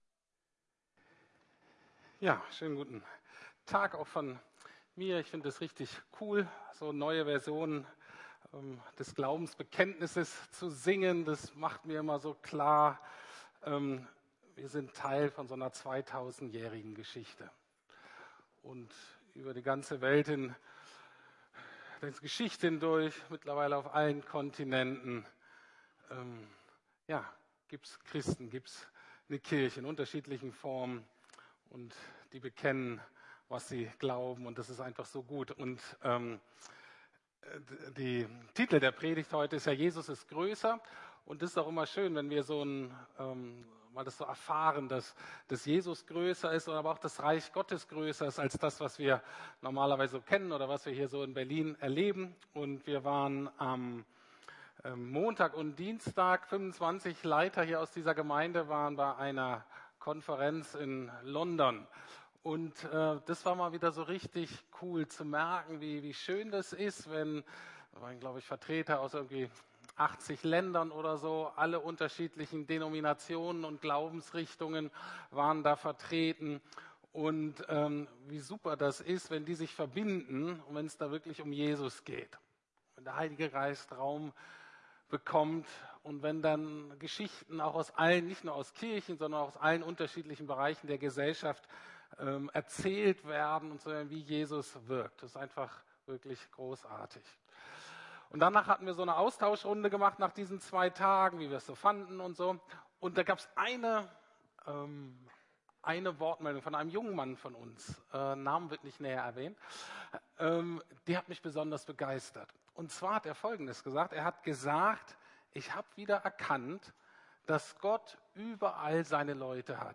Gott ist größer als meine Angst ~ Predigten der LUKAS GEMEINDE Podcast